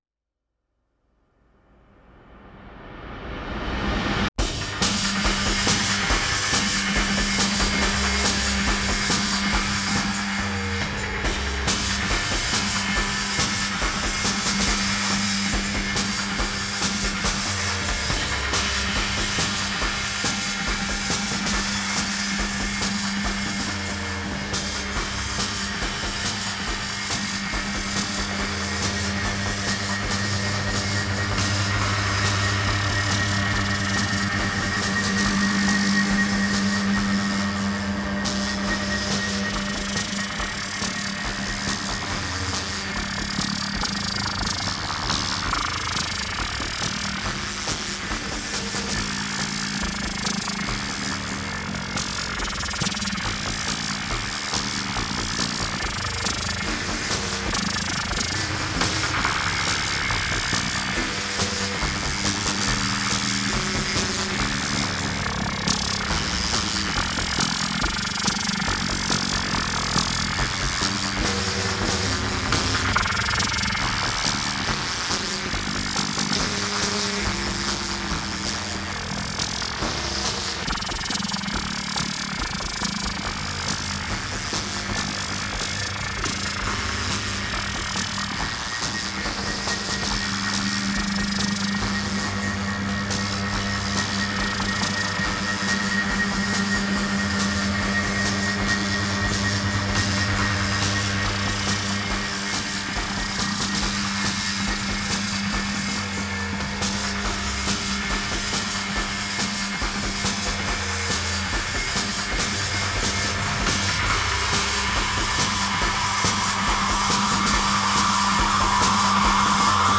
いずれもBPM=140で三連符を基調としています。
たいへんデリケートで複雑なパンニングと、スペクトル分布を特徴とするアルバムです。